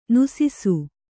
♪ nusisu